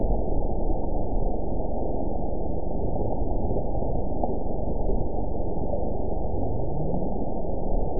event 912270 date 03/23/22 time 10:23:31 GMT (3 years, 1 month ago) score 9.43 location TSS-AB05 detected by nrw target species NRW annotations +NRW Spectrogram: Frequency (kHz) vs. Time (s) audio not available .wav